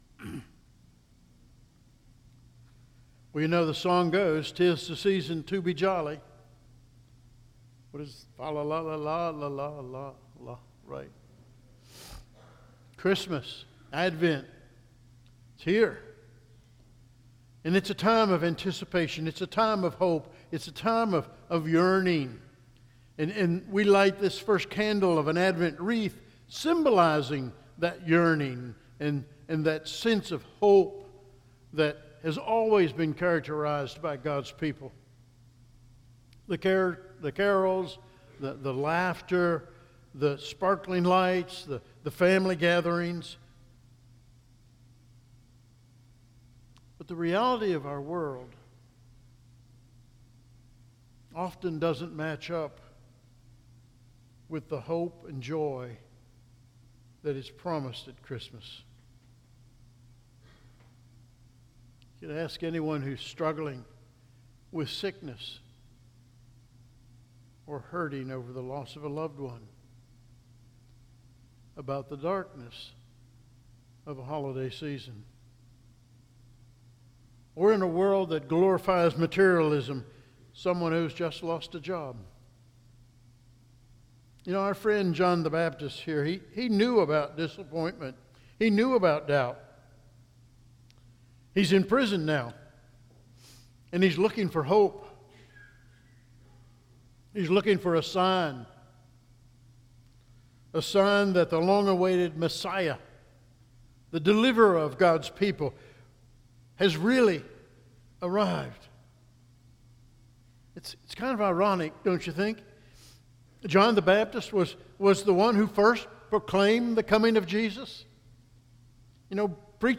Sermon audio from the pulpit of First Evangelical Presbyterian Church Roanoke